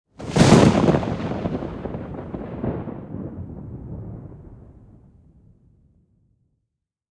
thunder_10.ogg